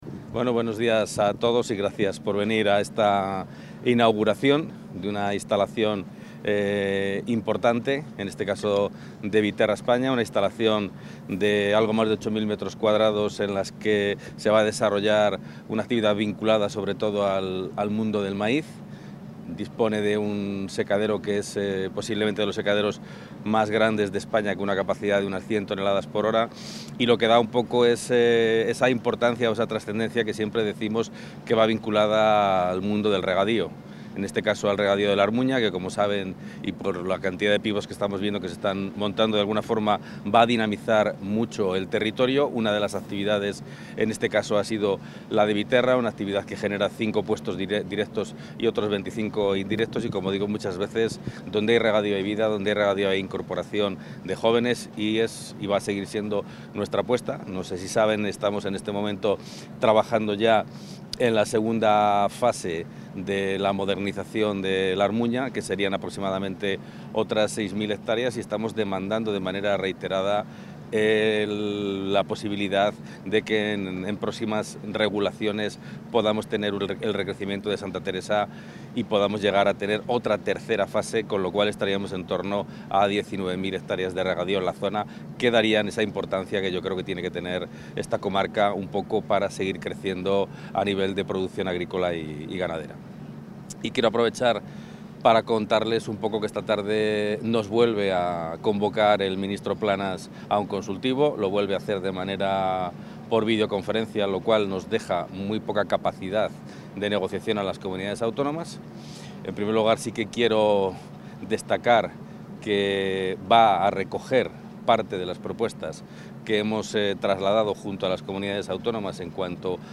Intervención del consejero.
Y en ellos se centran las reclamaciones que el consejero de Agricultura, Ganadería y Desarrollo Rural trasladará al ministro Planas en la reunión del Consejo Consultivo de esta tarde. Así lo ha anunciado Gerardo Dueñas en sus declaraciones a los medios durante su visita al nuevo secadero de maíz que la empresa Viterra Agrícola España ha inaugurado en Villaflores (Salamanca).